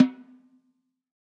Snare Zion 6.wav